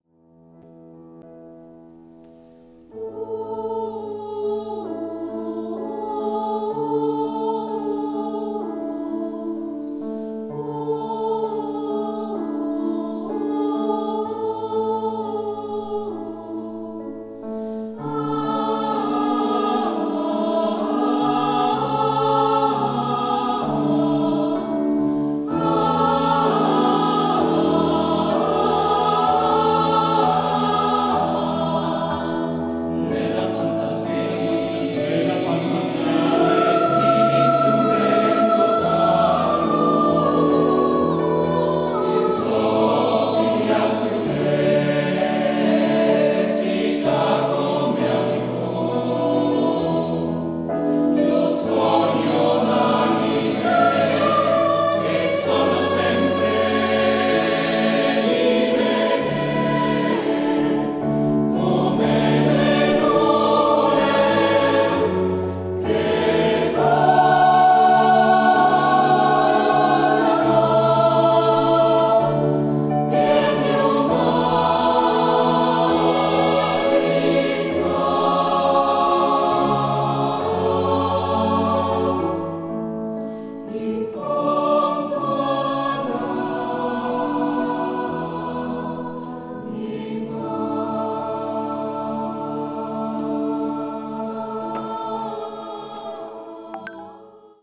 Audios en directo (concierto de estreno,  2 de Diciembre de 2017):
Dependiendo del estilo y del tema concreto, cantamos a capella o con instrumentación de acompañamiento opcional (teclado).